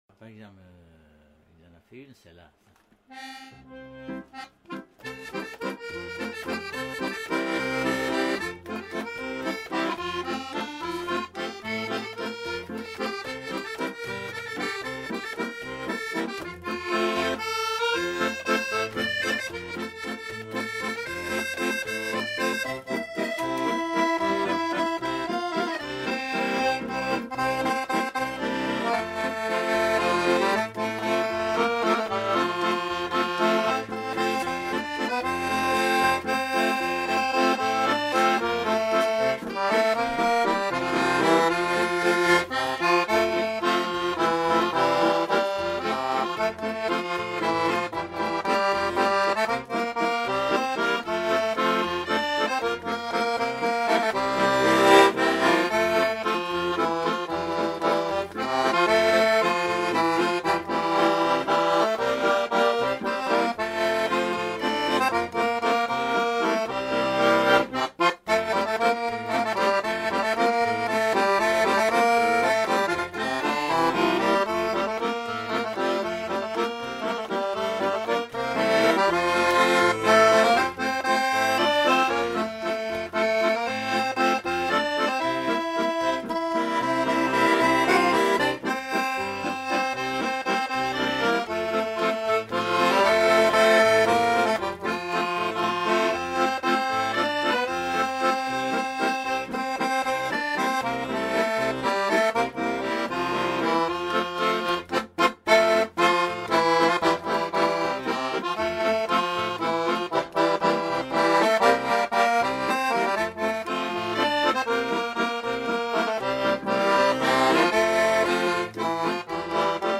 Aire culturelle : Quercy
Lieu : Altillac
Genre : morceau instrumental
Instrument de musique : accordéon chromatique
Danse : valse
Ecouter-voir : archives sonores en ligne